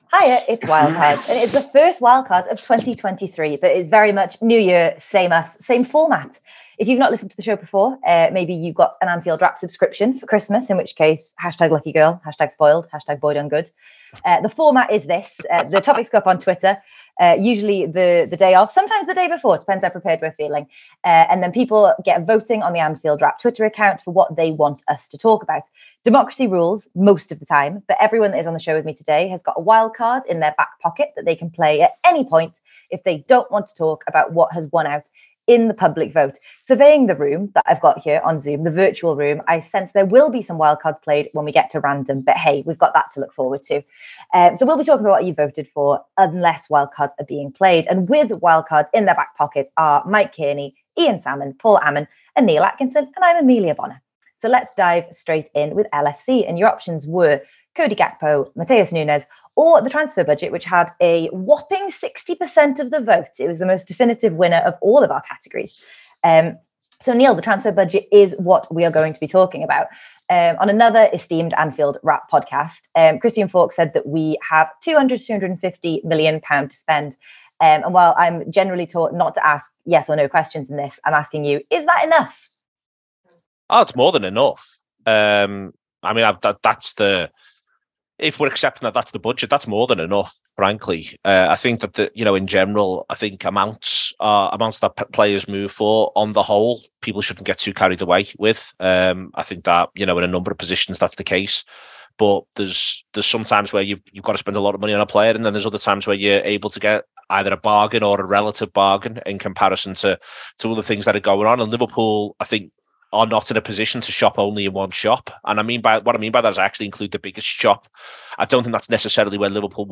The panel discuss Liverpool’s transfer budget amid reports of war chests, the FA Cup third round results, the darts and Prince Harry.